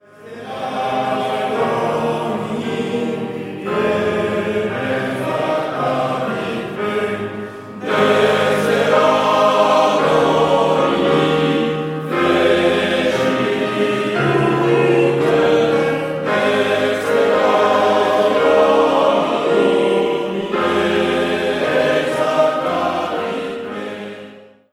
Chants liturgiques